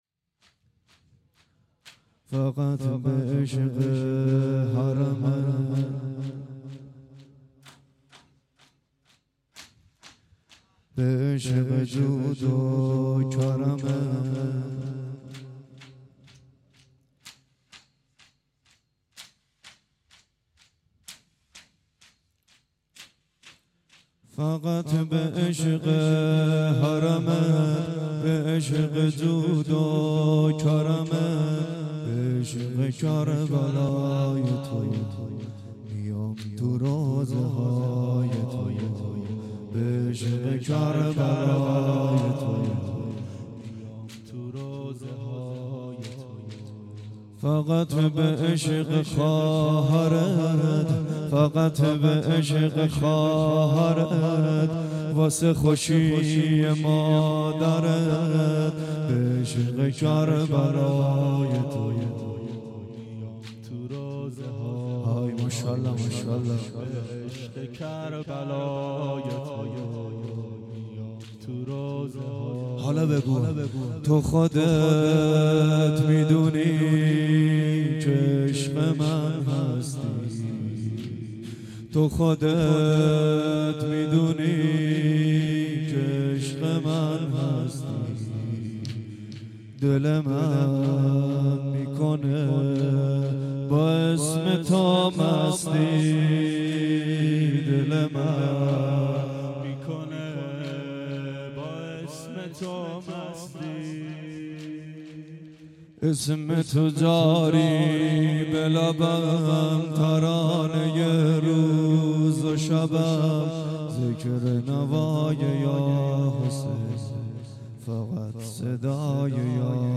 دهه اول صفر سال 1391 هیئت شیفتگان حضرت رقیه سلام الله علیها (شب اول)